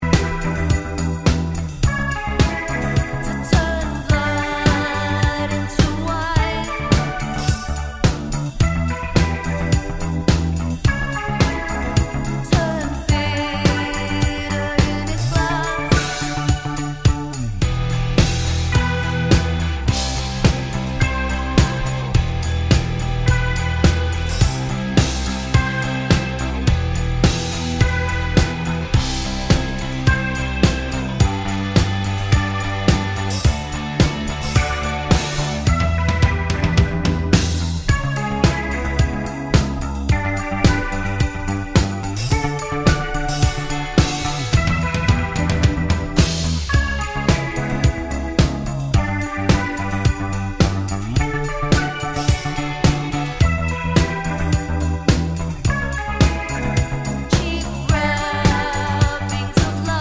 no Backing Vocals Punk 4:53 Buy £1.50